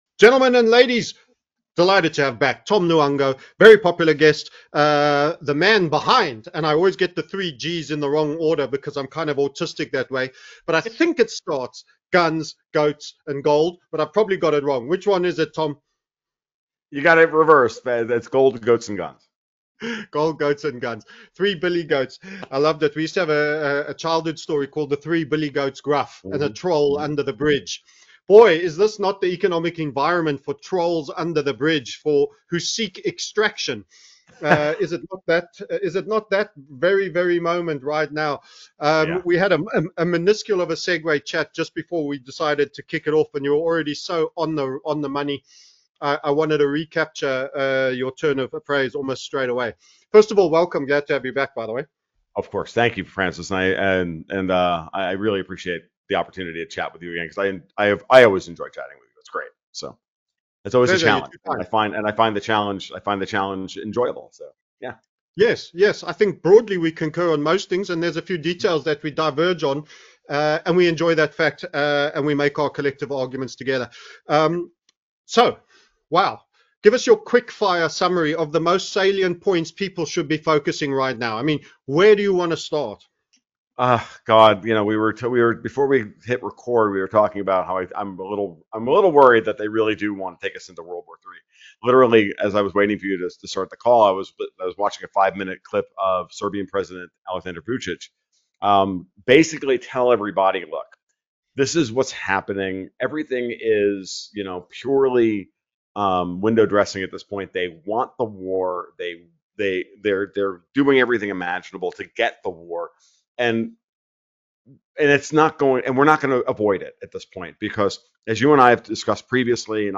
The Financial Consequences of a World War. A discussion